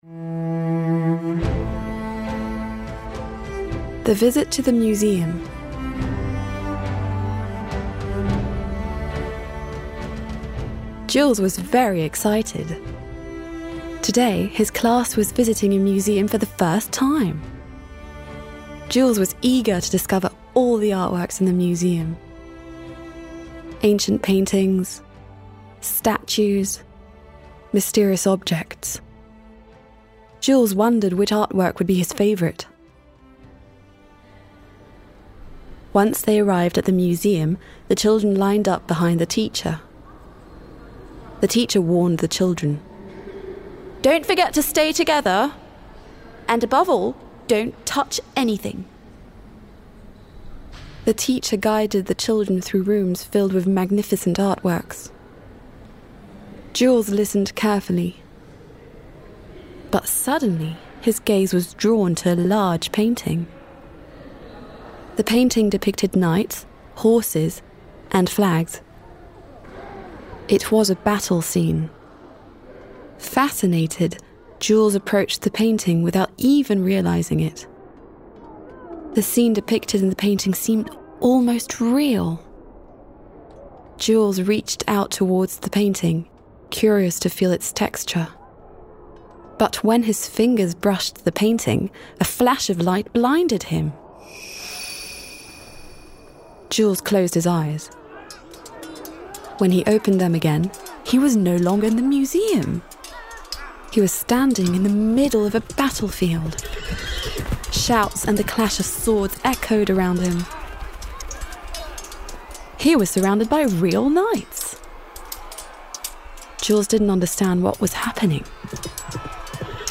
Voix-off pour Bilibox, conteuse audio bilingue
16 - 30 ans - Soprano